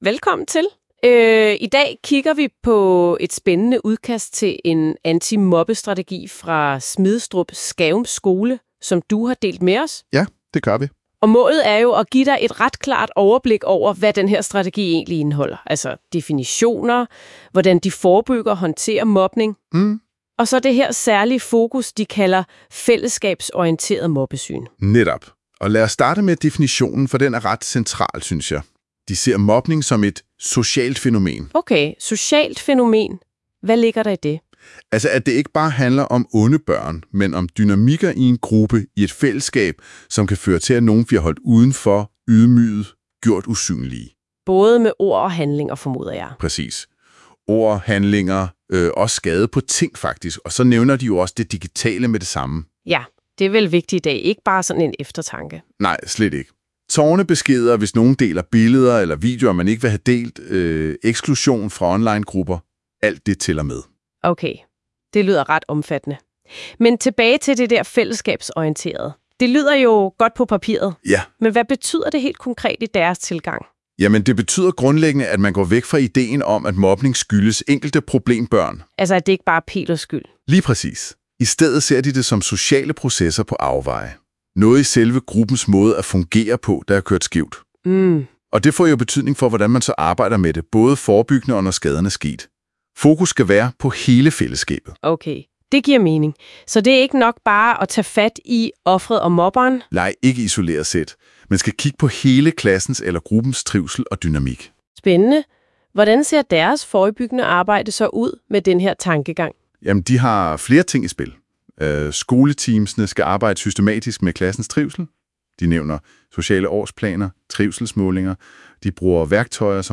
Antimobbestrategien er også, ved hjælp af Googles AI-værktøj, omdannet til en 5 min. podcast, som man kan lytte til.